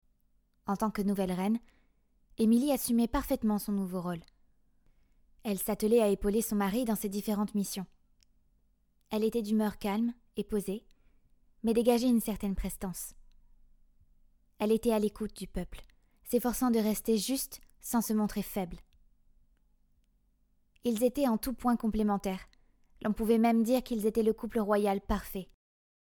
Extrait brut
3 - 30 ans - Mezzo-soprano